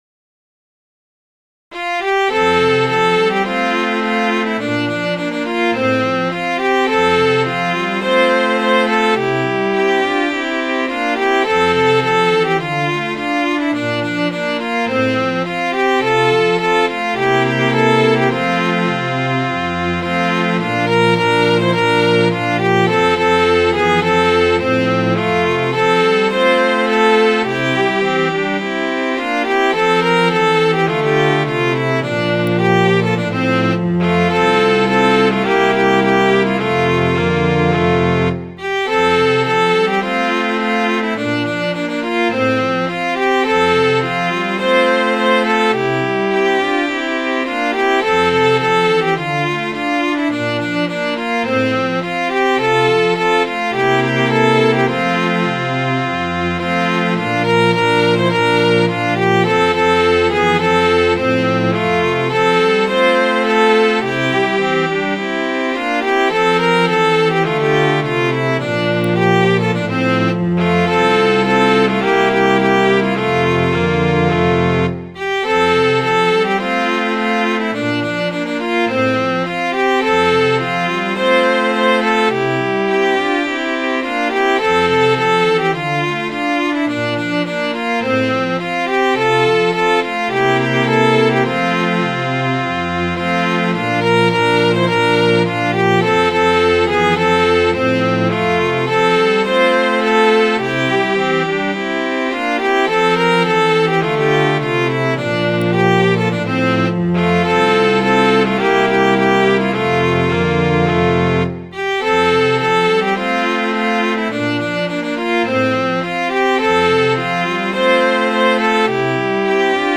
Midi File, Lyrics and Information to Jesse James